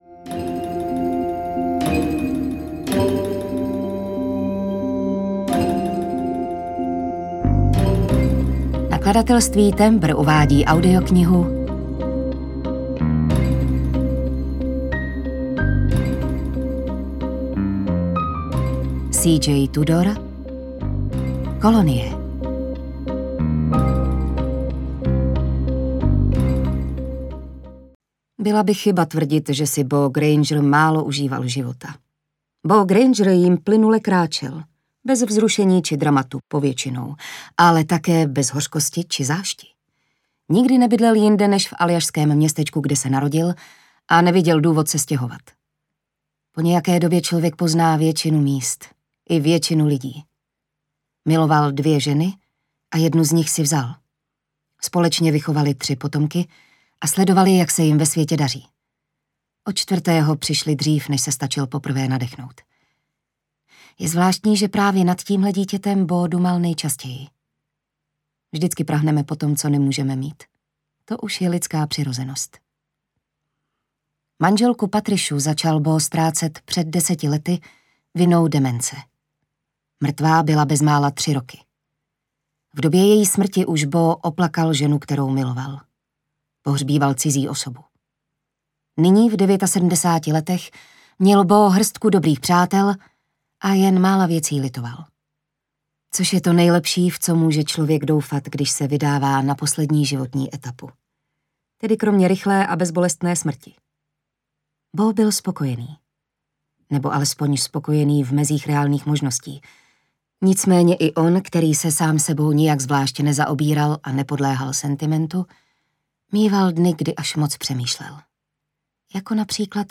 Kolonie audiokniha
Ukázka z knihy